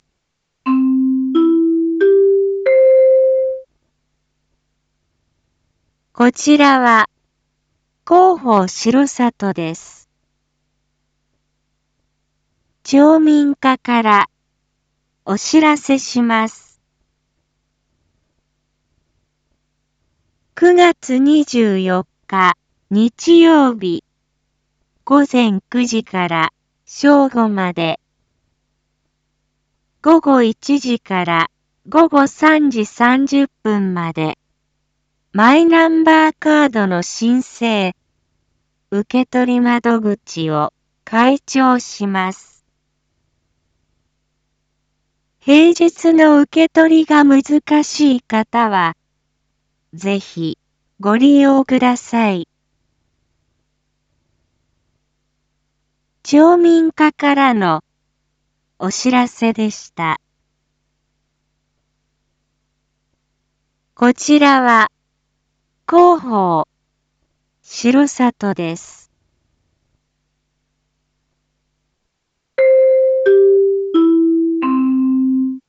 一般放送情報
Back Home 一般放送情報 音声放送 再生 一般放送情報 登録日時：2023-09-23 19:01:17 タイトル：マイナンバーカード インフォメーション：こちらは、広報しろさとです。